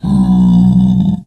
Sound / Minecraft / mob / zombiepig / zpig1.ogg